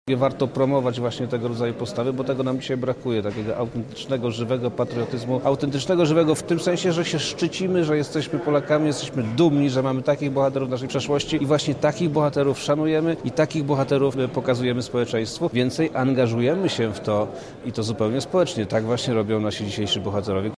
-mówi Przemysław Czarnek, wojewoda lubelski